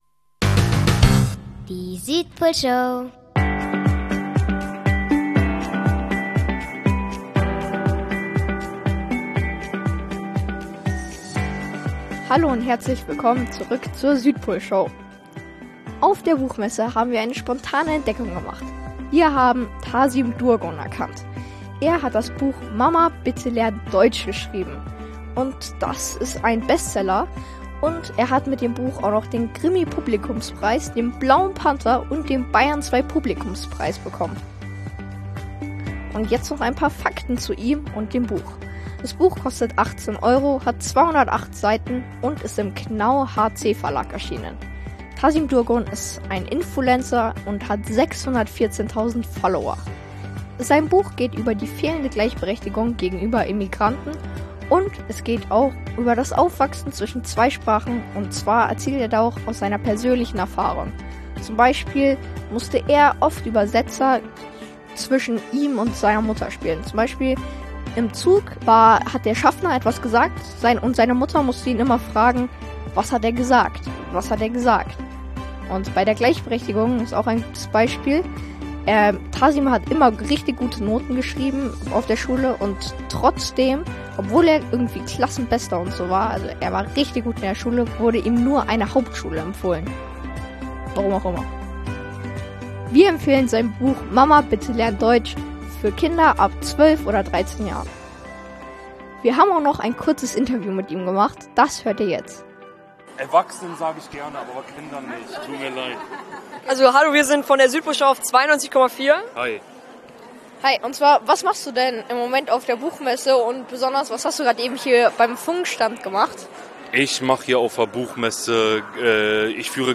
Kurzinterview auf Frankfurter Buchmesse 2025